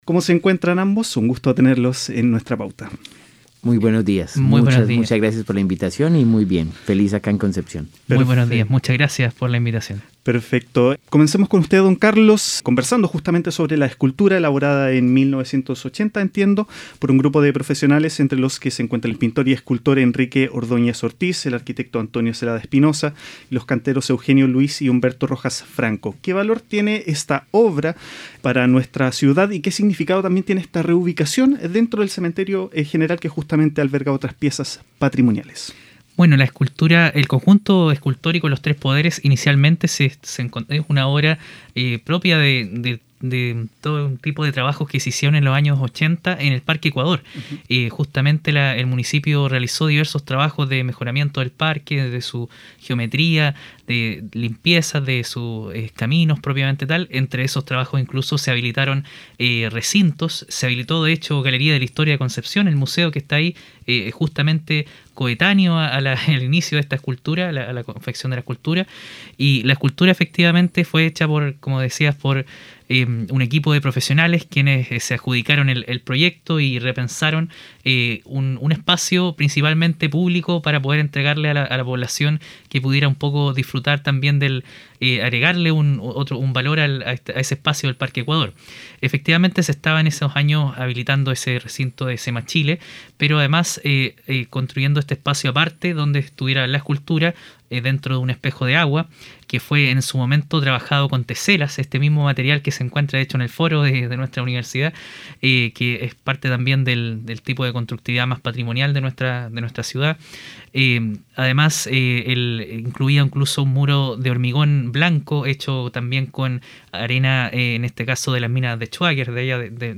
Entrevista-cementerios-patrimoniales.mp3